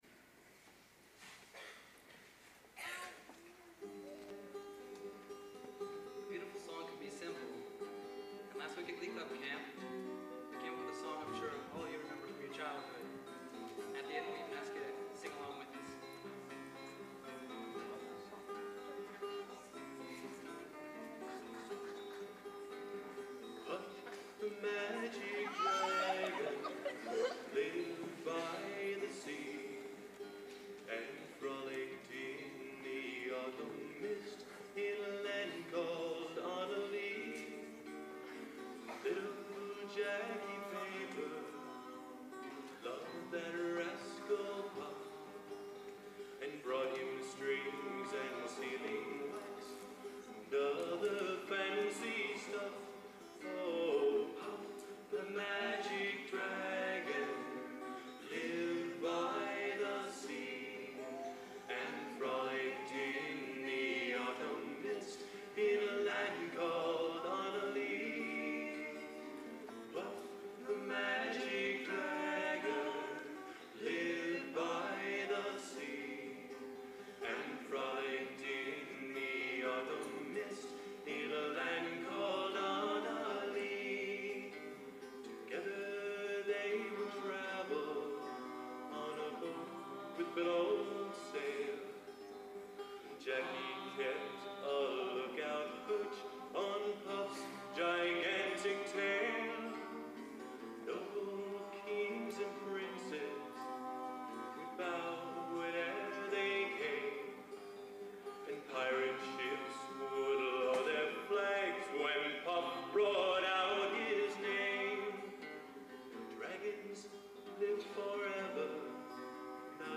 Location: Purdue Memorial Union, West Lafayette, Indiana
Genre: Folk | Type: Featuring Hall of Famer |Specialty